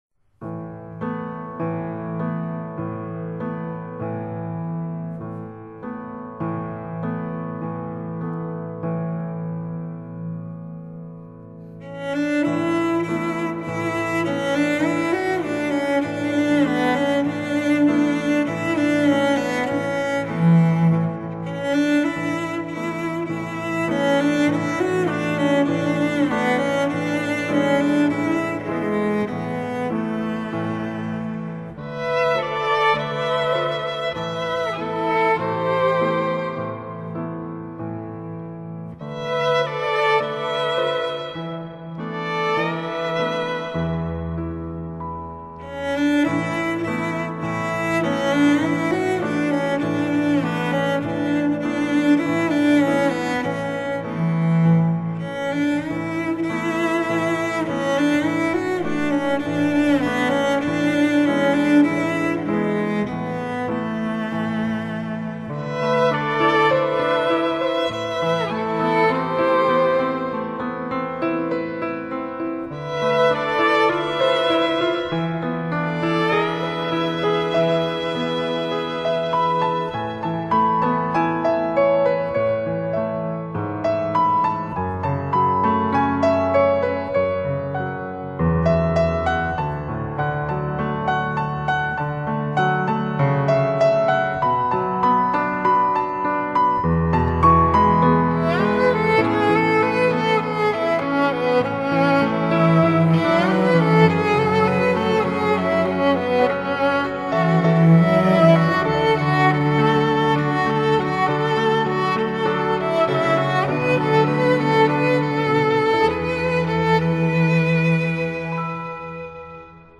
演繹浪漫的流行樂曲